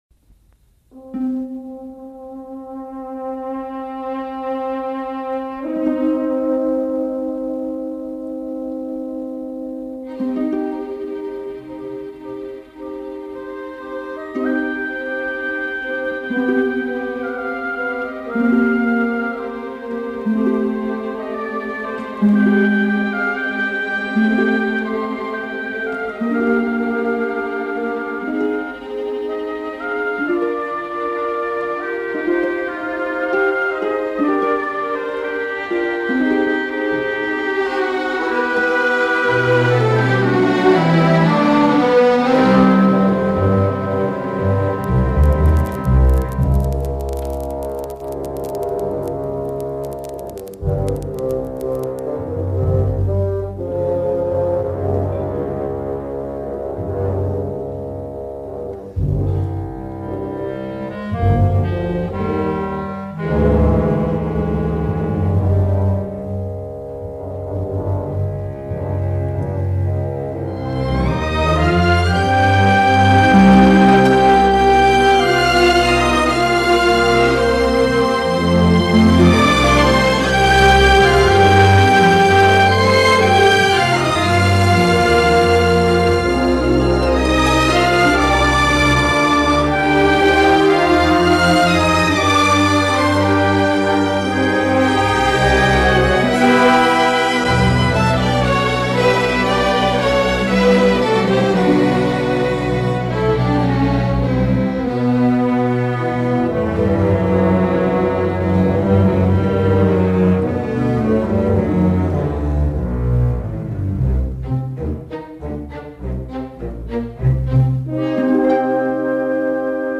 Аудиокнига Люди с чистой совестью
Качество озвучивания весьма высокое.